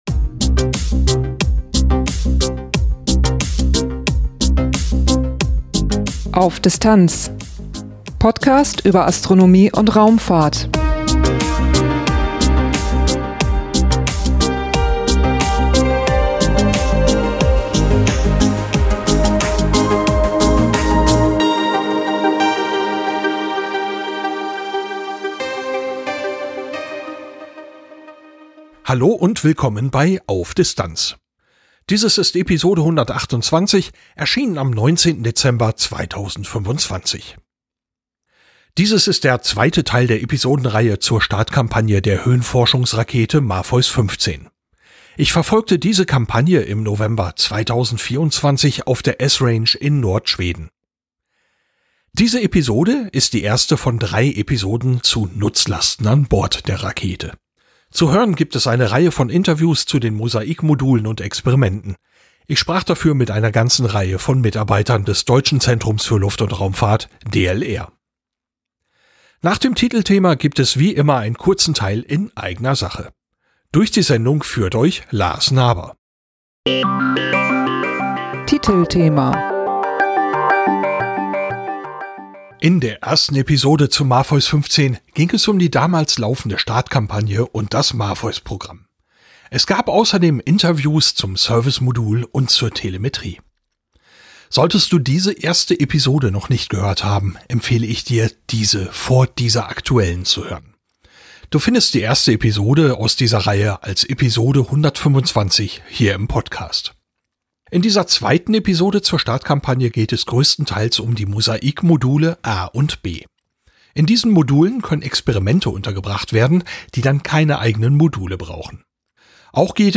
Dieses ist der zweite Teil der Episodenreihe zur Startkampagne der Höhenforschungsrakete MAPHEUS-15. Ich verfolgte diese Kampagne im November 2024 auf der Esrange in Nord-Schweden.
Zu hören gibt es eine Reihe von Interviews zu den MOSAIC-Modulen und Experimenten.